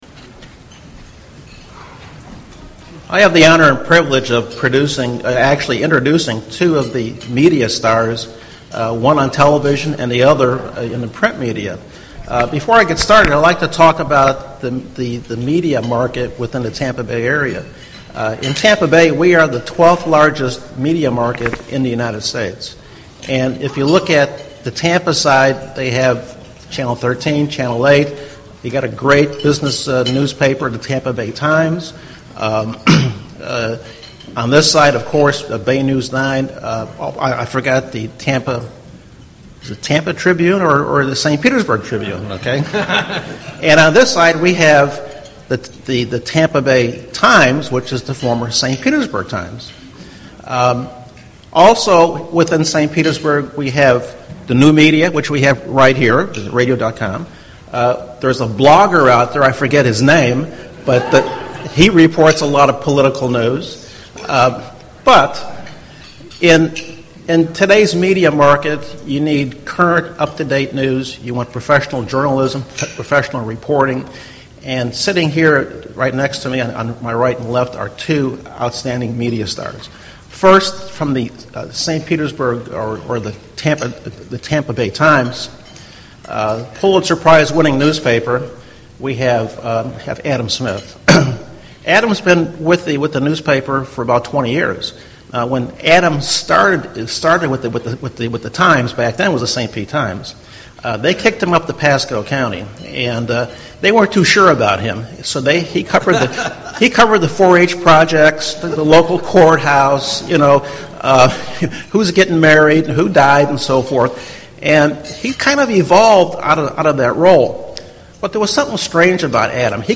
at Suncoast Tiger Bay Club 11-7-13